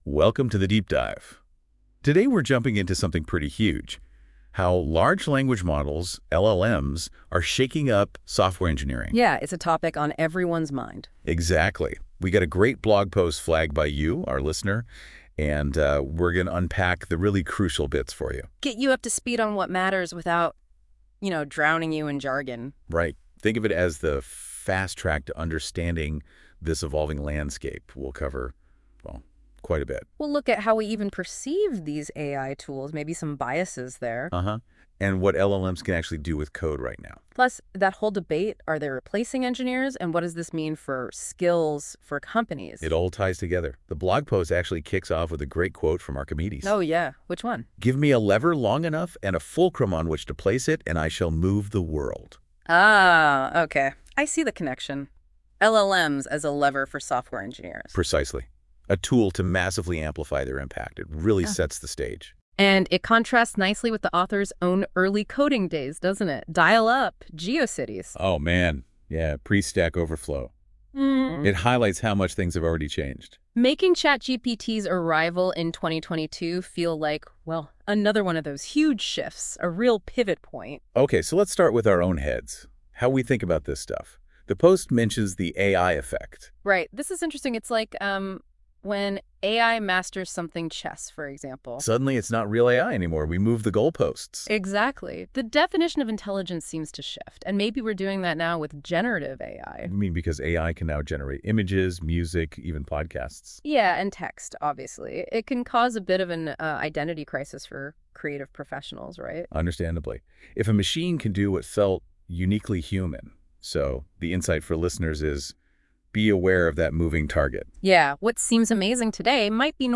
AI-generated podcast version of this blog post, courtesy of Google's NotebookLM.